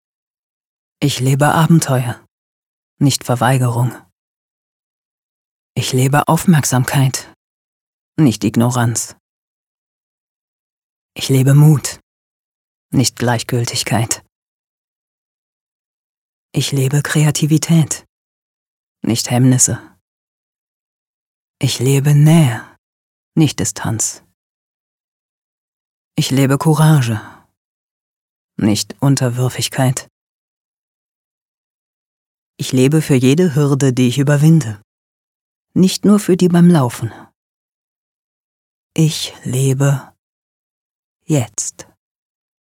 markant, sehr variabel, dunkel, sonor, souverän
Mittel plus (35-65)
Norddeutsch
Werbung - Statement "Jetzt"
Commercial (Werbung), Off, Comment (Kommentar)